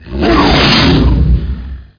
1 channel
WAMPROAR.mp3